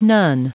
Prononciation du mot none en anglais (fichier audio)
Prononciation du mot : none